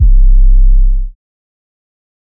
old metro 808.wav